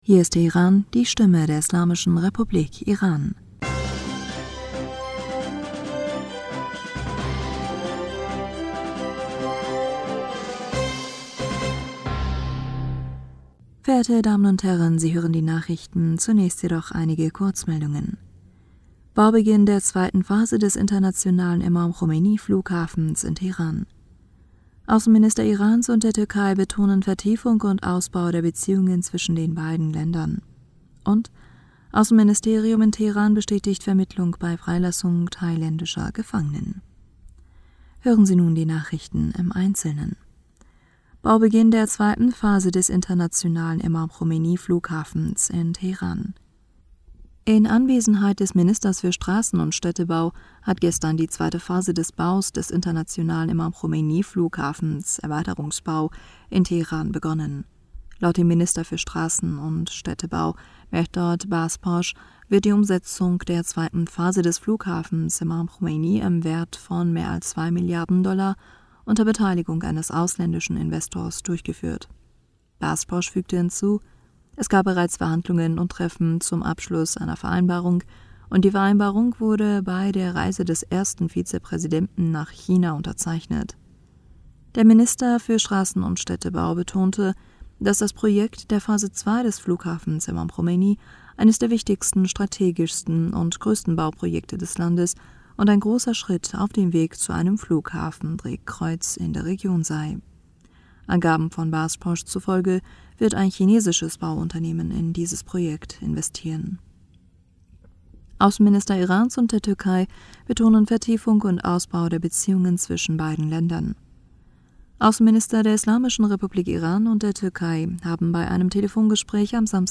Nachrichten vom 26. November 2023